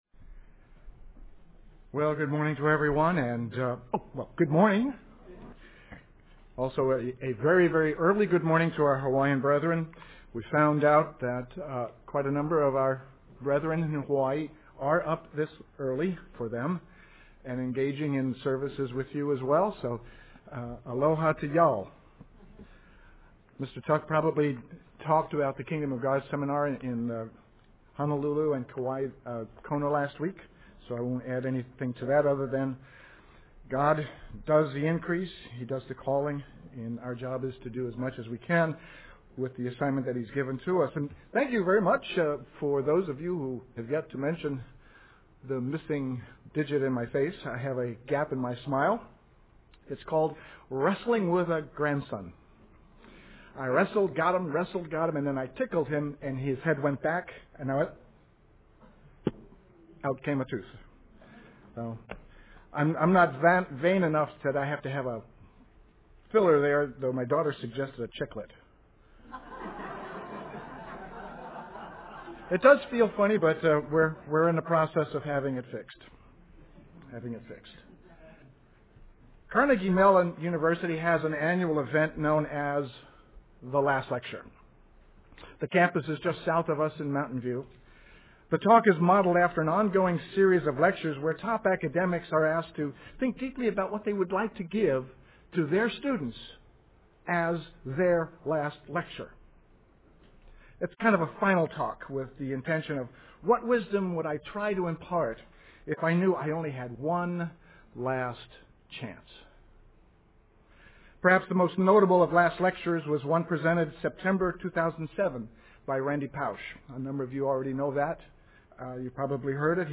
Sermons
Given in San Francisco Bay Area, CA San Jose, CA